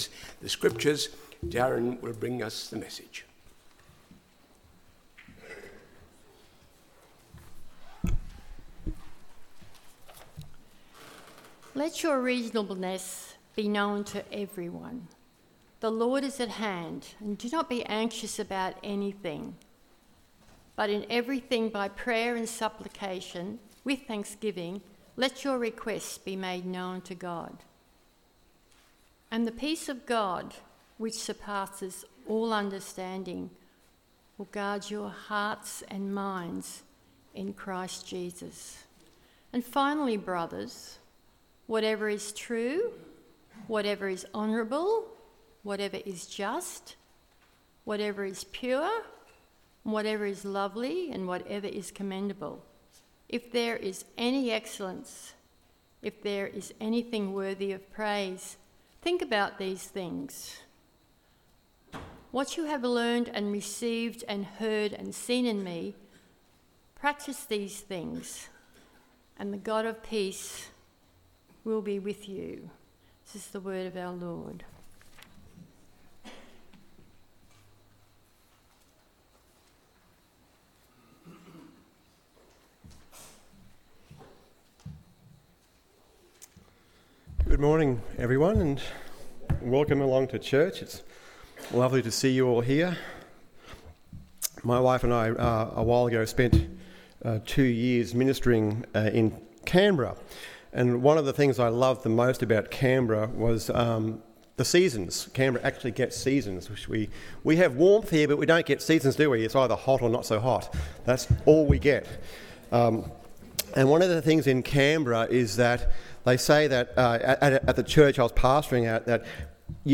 Why Worry Now? AM Service
sermon podcasts